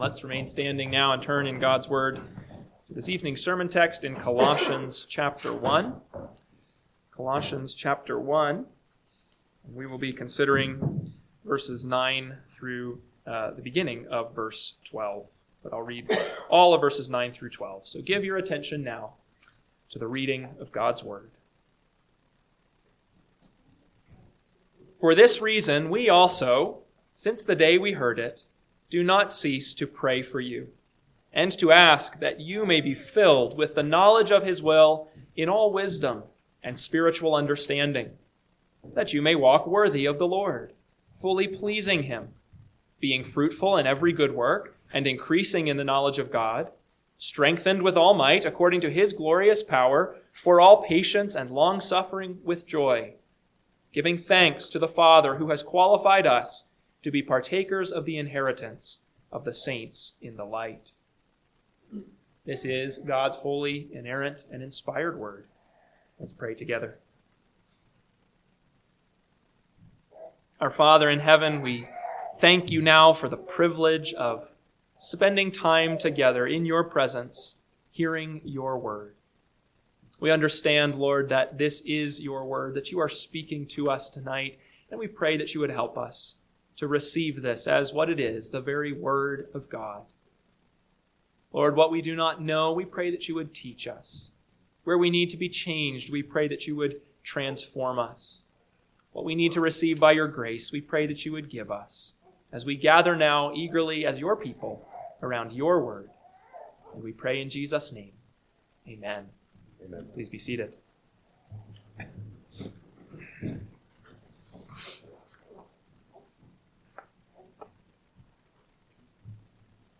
PM Sermon – 12/28/2025 – Colossians 1:9-12a – Northwoods Sermons